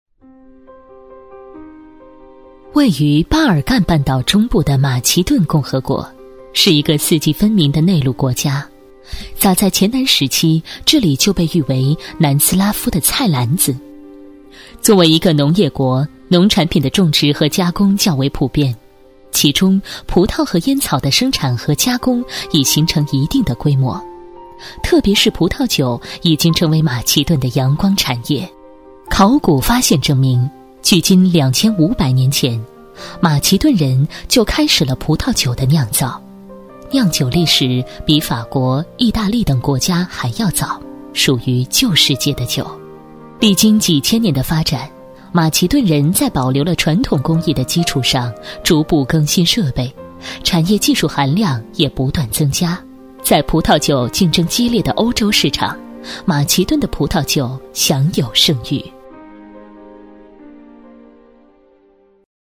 女国29_纪录片_自然科教_马其顿的葡萄酒.mp3